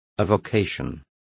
Complete with pronunciation of the translation of avocations.